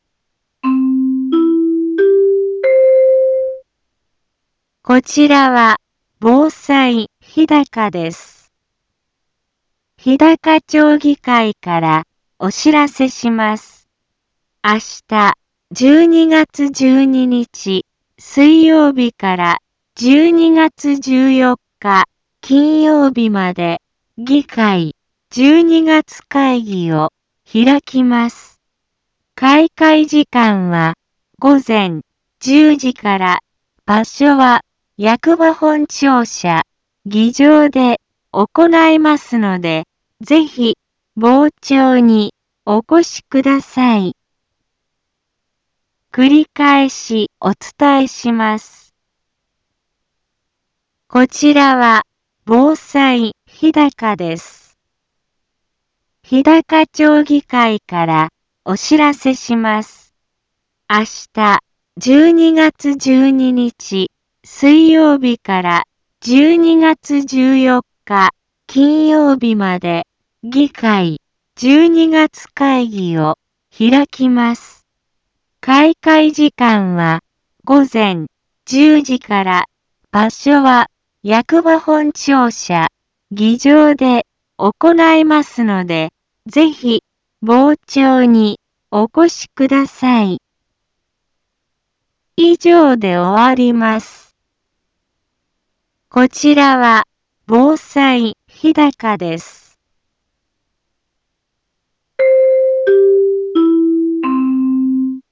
Back Home 一般放送情報 音声放送 再生 一般放送情報 登録日時：2018-12-11 10:03:47 タイトル：日高町議会12月会議のお知らせ インフォメーション：こちらは、防災日高です。